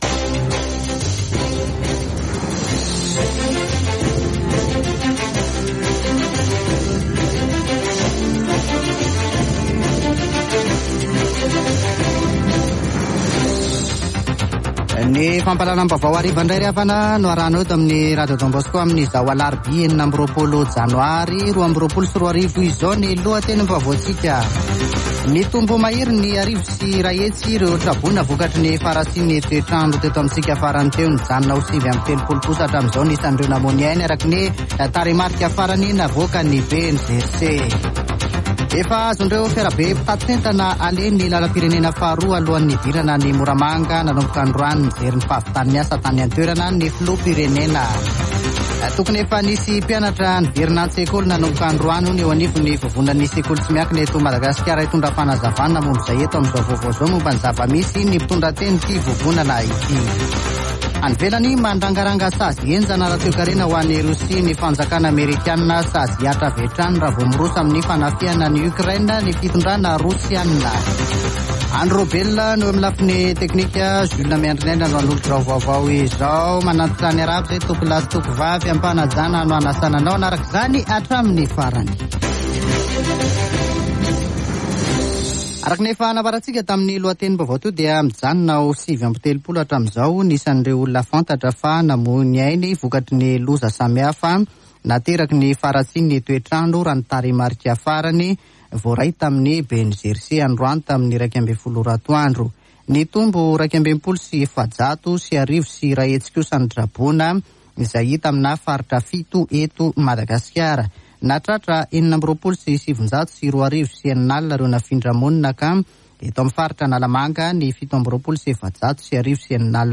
[Vaovao hariva] Alarobia 26 janoary 2022